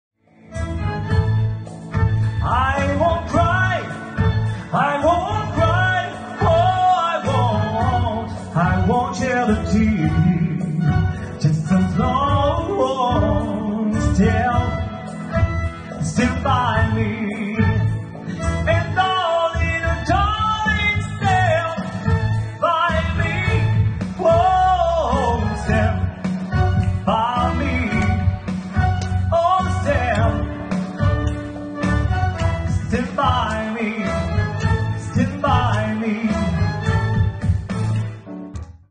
Chanteur